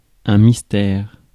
Ääntäminen
IPA : /ˈpʌz.əl/